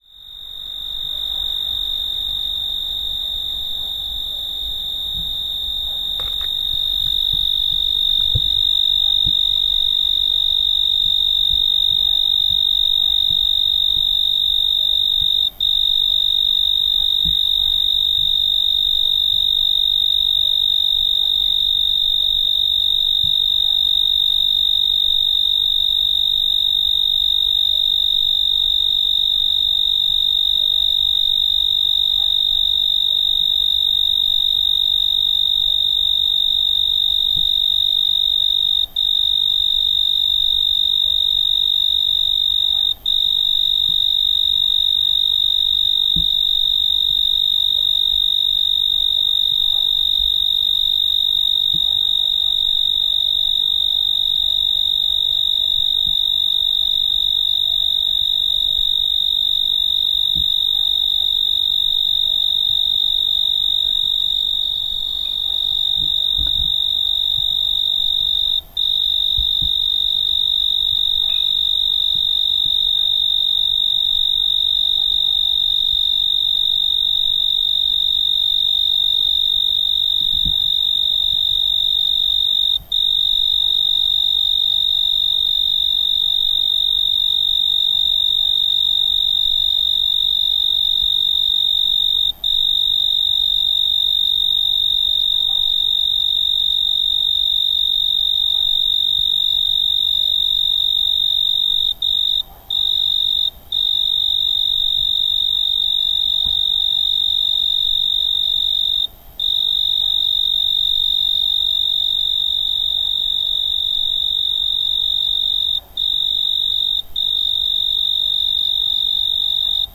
Crickets in Scopello
Crickets recorded at 1am in Scopello, Sicily by Cities and Memory.